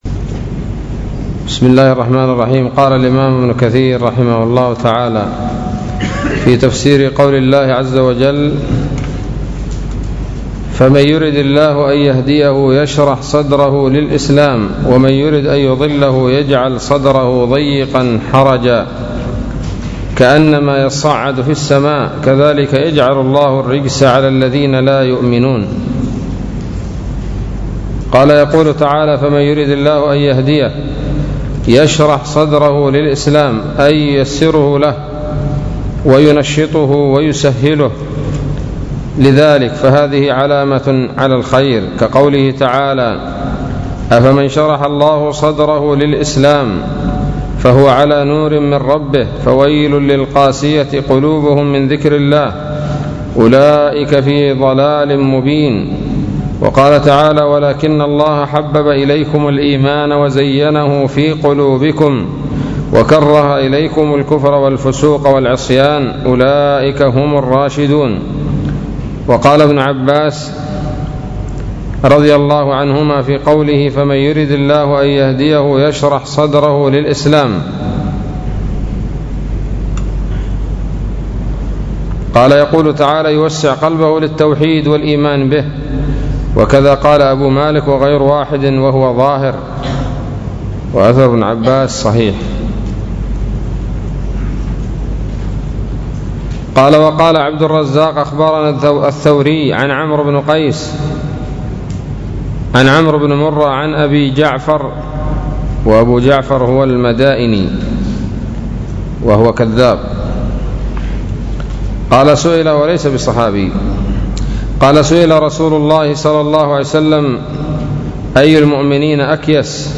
الدرس الثامن والأربعون من سورة الأنعام من تفسير ابن كثير رحمه الله تعالى